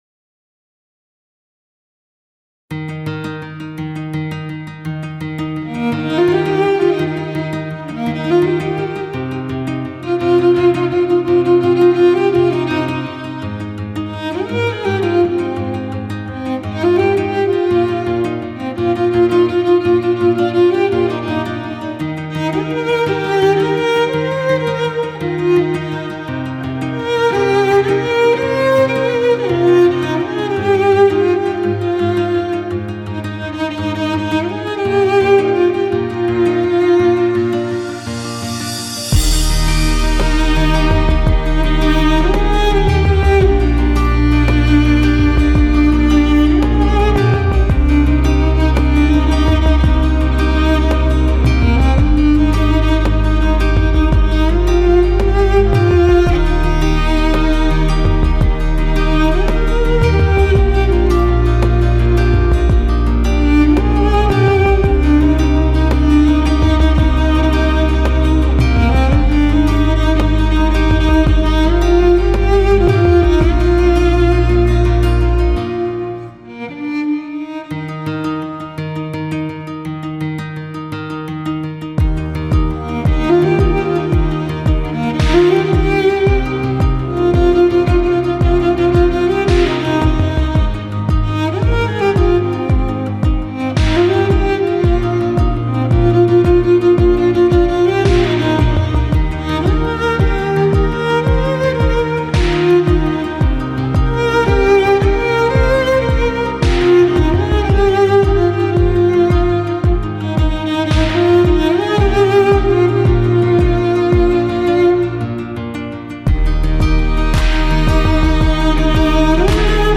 Female Solo Cellist